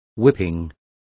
Also find out how azotamientos is pronounced correctly.